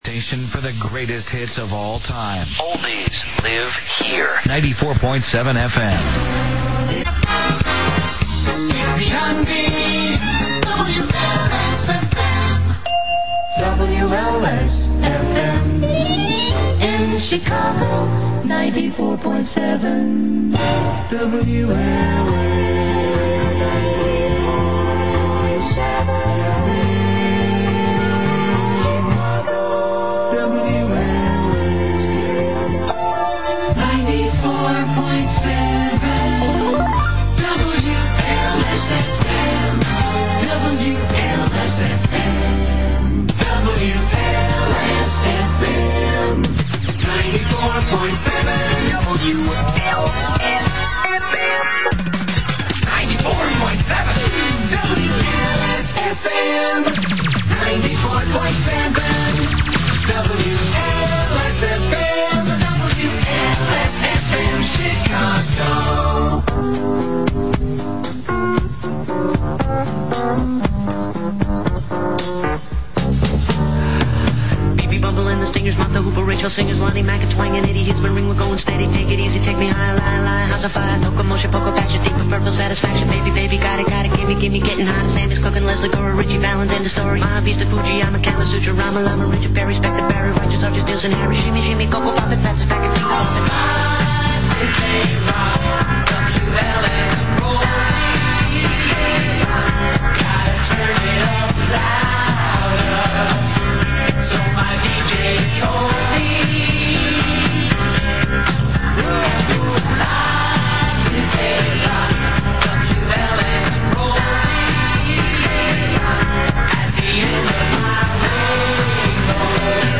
Aircheck of 94.7's first day as WLS-FM